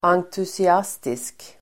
Uttal: [angtusi'as:tisk (el. en-)]
entusiastisk.mp3